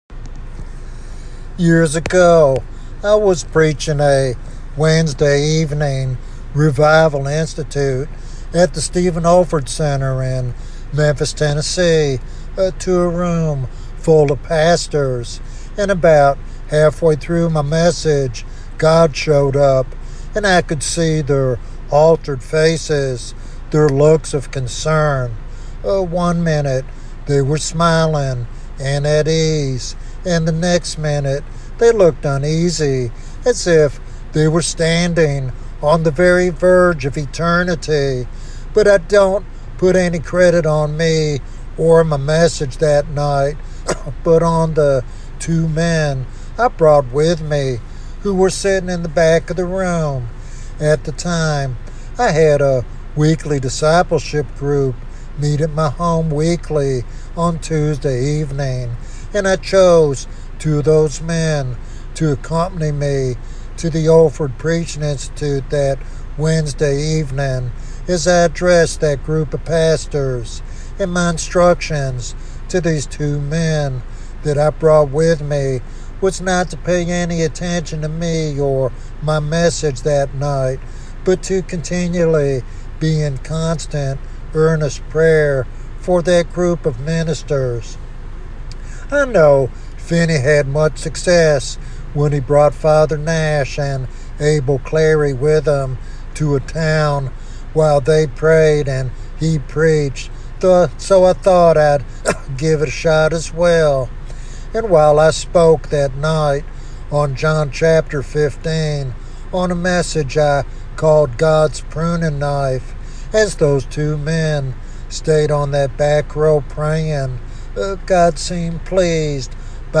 In this powerful expository sermon